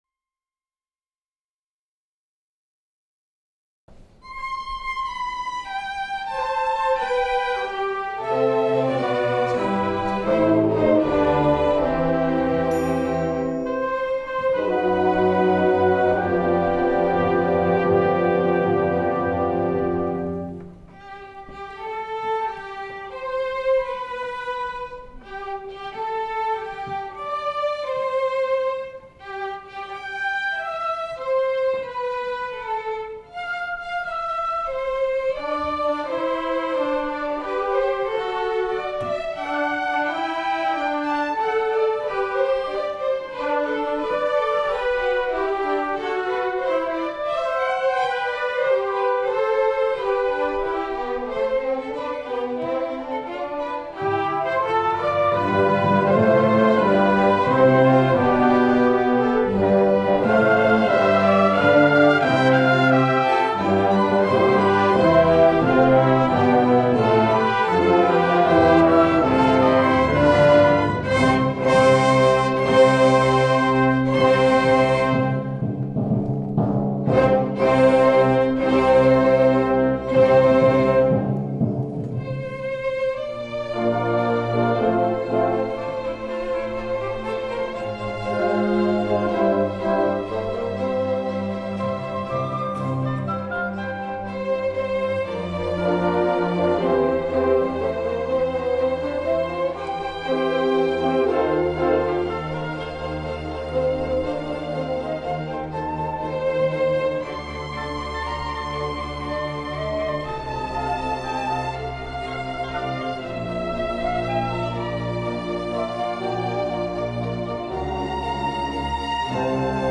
Music for Orchestra